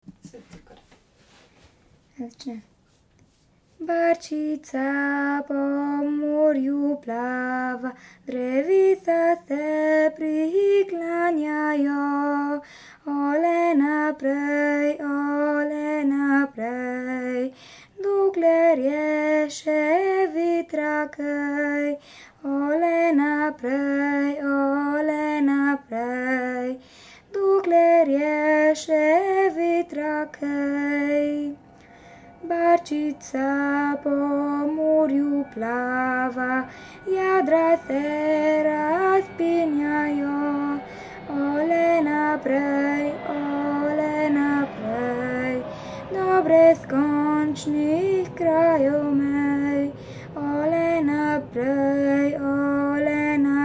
533 - Music
3.7. - PEVSKA KULTURA